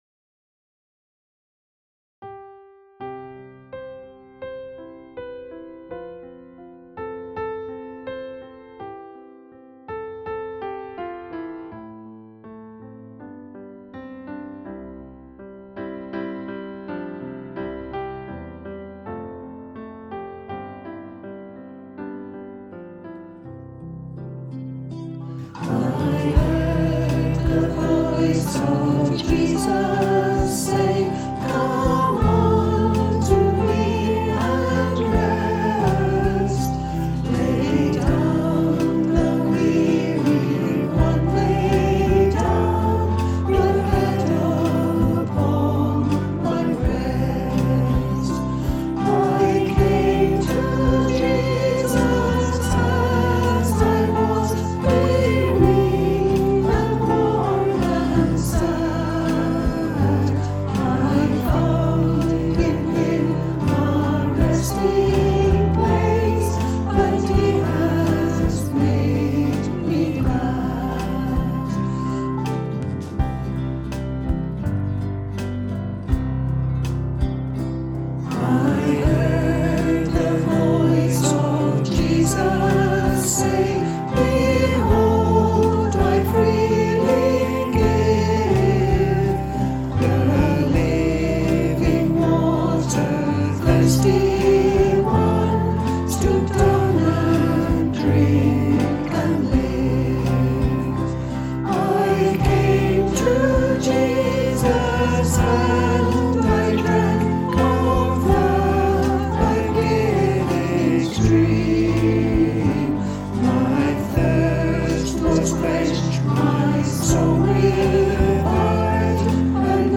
Praise Band At Home
Whilst we are unable to be together to play, the praise band and friends have been recording for the message each week.